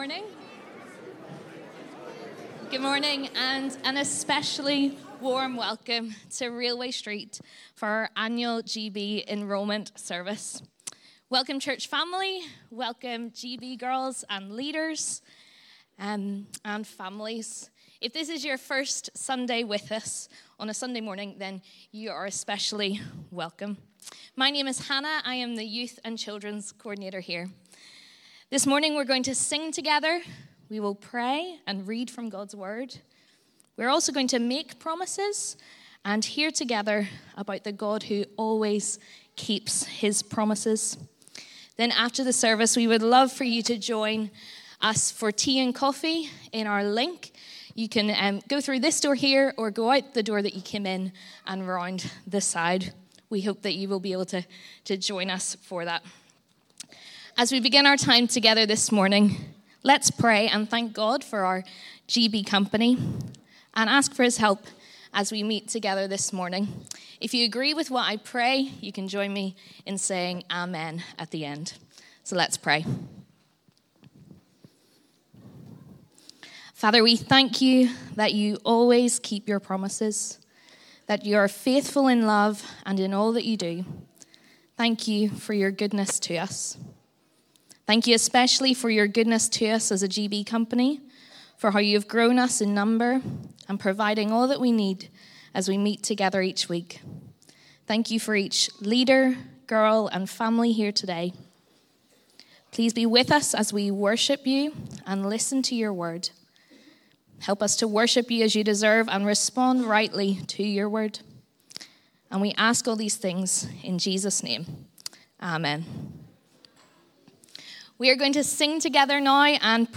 Railway Street Girls' Brigade Enrolment Service 2023